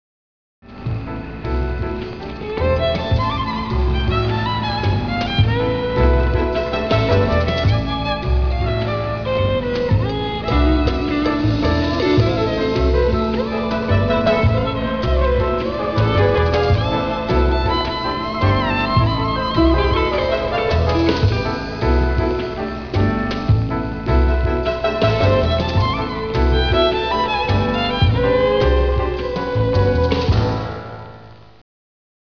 TV show theme